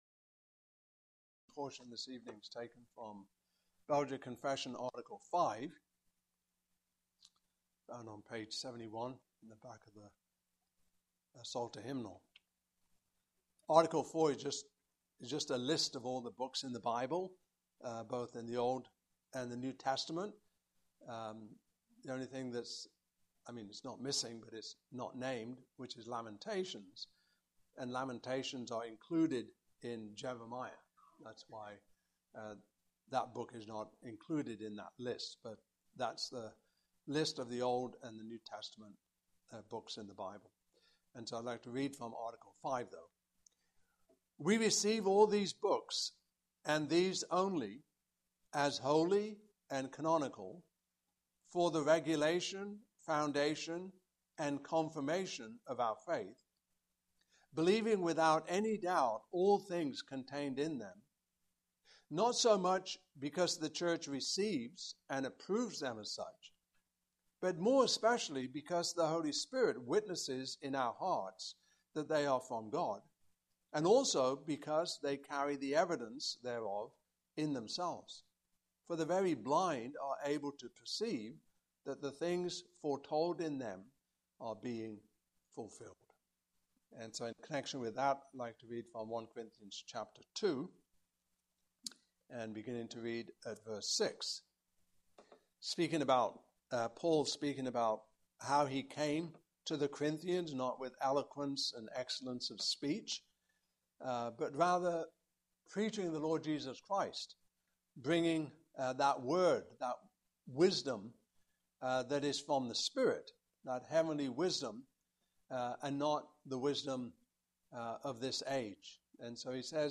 Passage: I Corinthians 2:6-16 Service Type: Evening Service